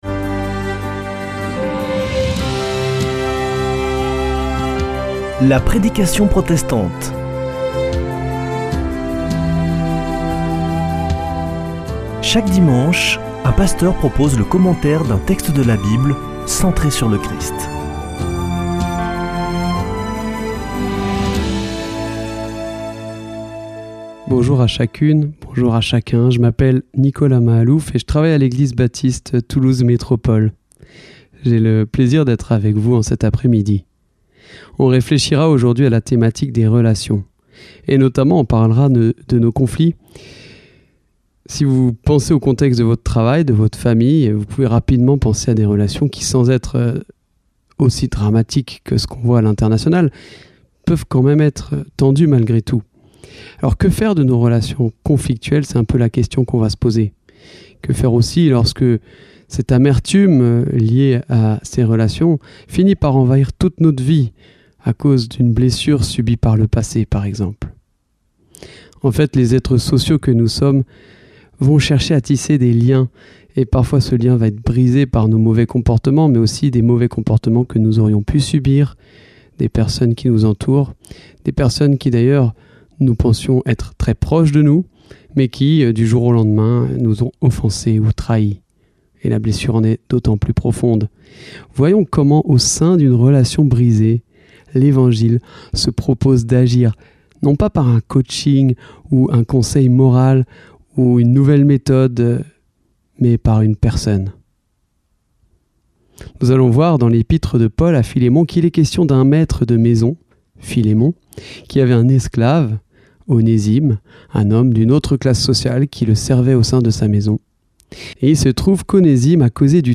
La prédication protestante
Partager Copier ce code (Ctrl+C) pour l'intégrer dans votre page : Commander sur CD Une émission présentée par Des protestants de la région Présentateurs Voir la grille des programmes Nous contacter Réagir à cette émission Cliquez ici Qui êtes-vous ?